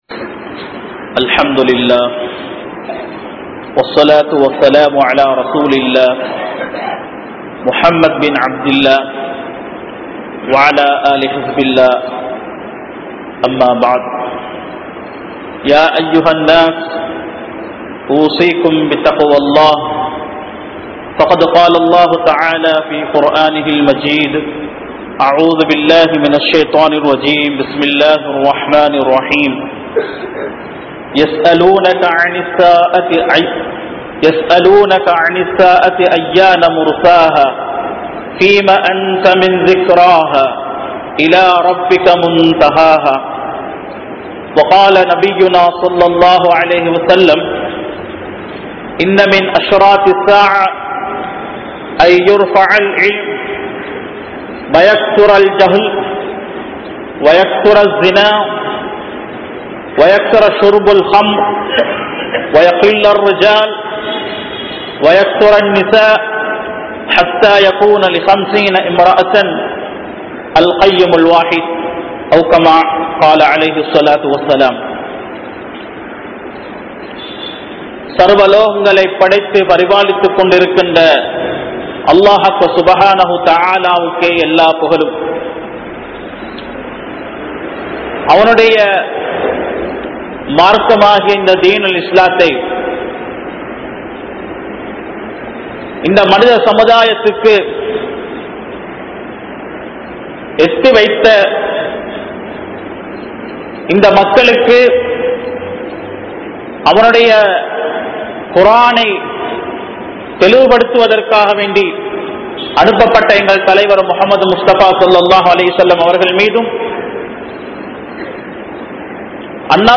Signs Of Qiyama (கியாமத் நாளின் அடையாளங்கள்) | Audio Bayans | All Ceylon Muslim Youth Community | Addalaichenai